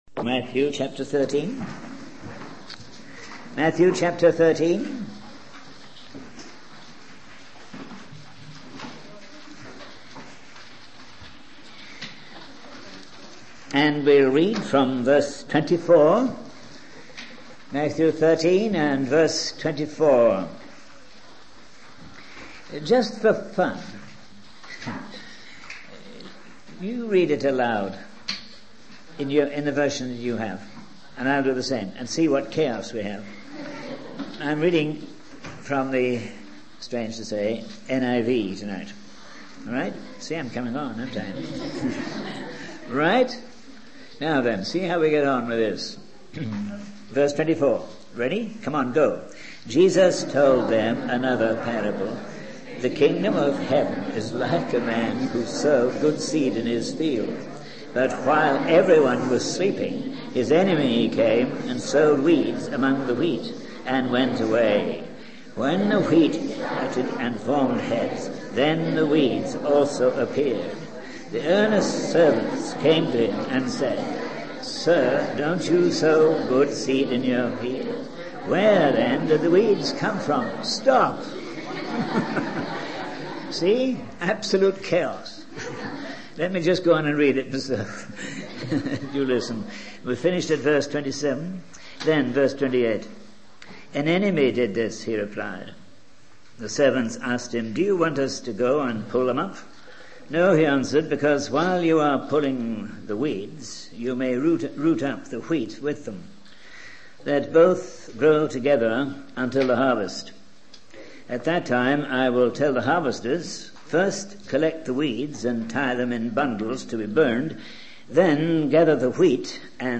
In this sermon, the preacher emphasizes that God is sending his children into all corners of the world to fulfill his purpose.